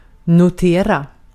Ääntäminen
US : IPA : /noʊt/ UK : IPA : /nəʊt/